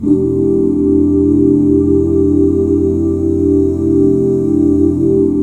FMAJ7 OOO -R.wav